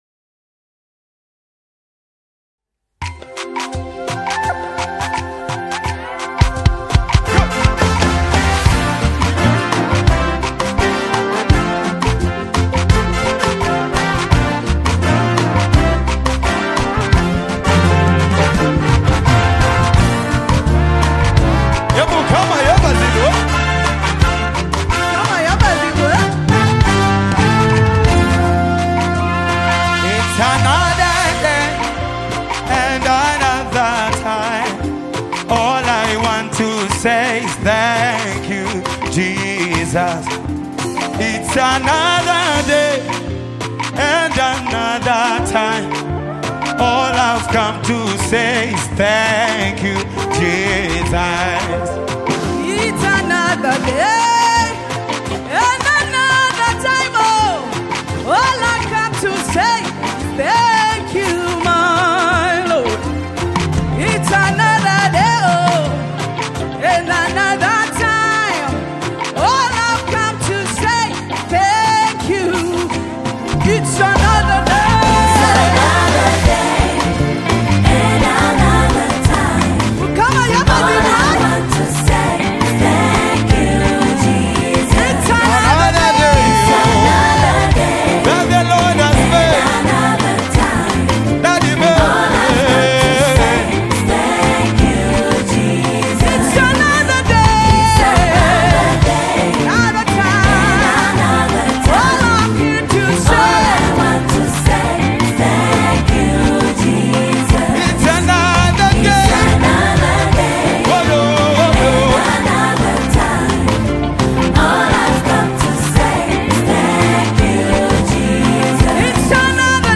Live
Gospel song